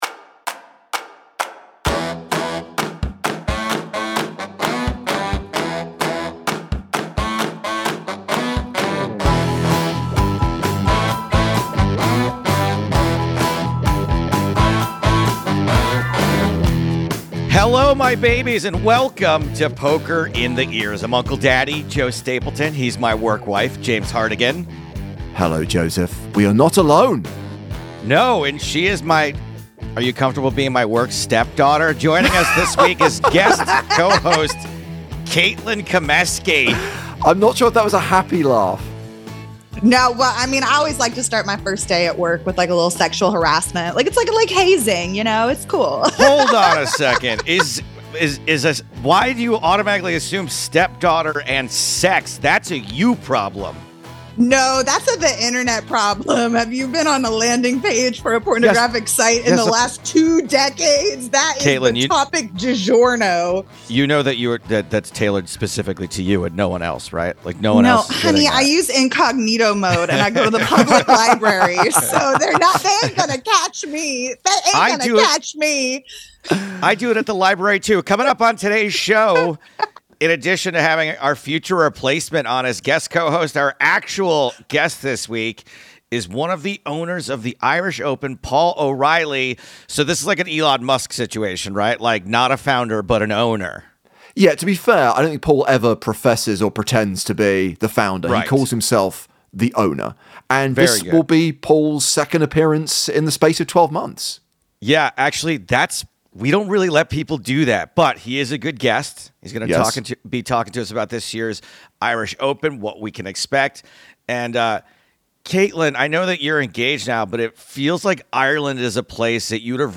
The hosts also regularly call in favours, in the form of guest spots from their big-shot celebrity poker-playing friends.